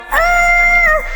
TS - CHANT (11).wav